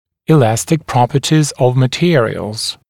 [ɪ’læstɪk ][и’лэстик ]эластичные характеристики (свойства) материалов